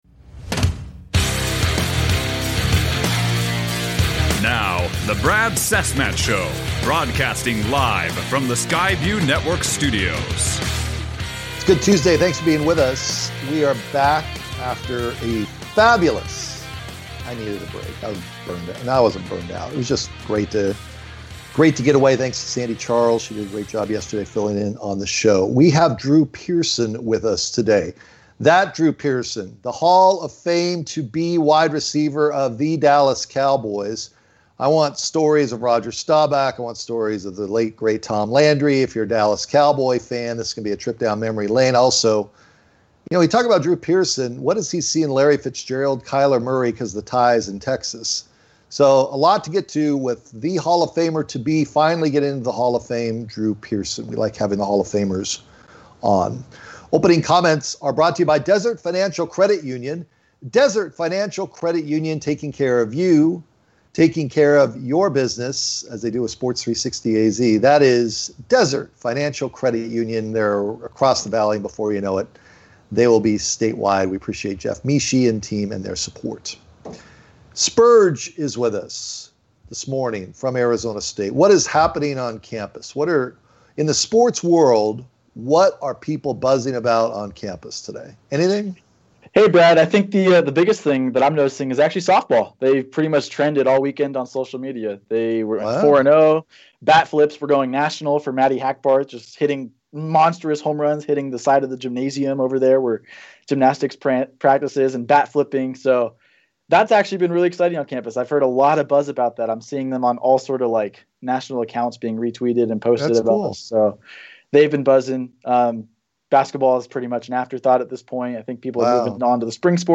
Hall of fame WR Drew Pearson (9:51 on podcast) joined the show to tallk about his hall of fame nomination, stories of Cowboys greats, and what he sees from Kyler Murray and Larry Fitzgerald.